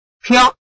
拼音查詢：【饒平腔】piog ~請點選不同聲調拼音聽聽看!(例字漢字部分屬參考性質)